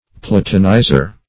Platonizer \Pla"to*ni`zer\, n. One who Platonizes.